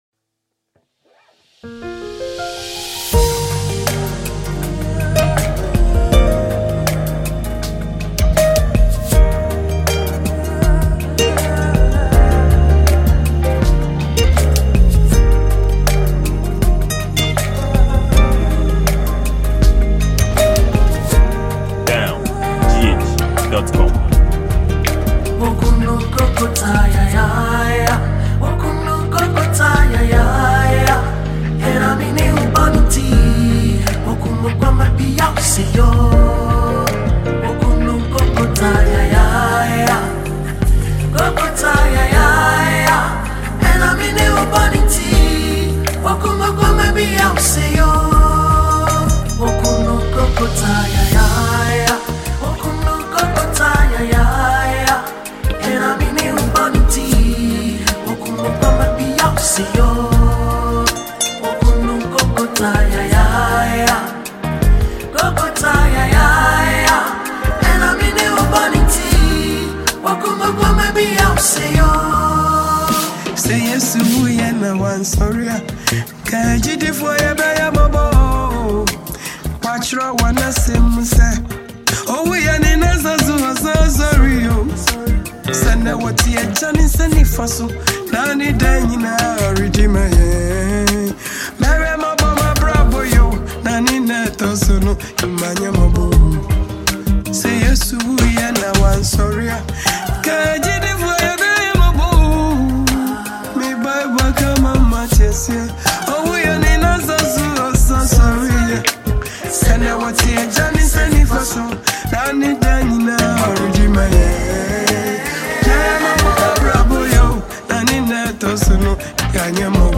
Ghana Music, Gospel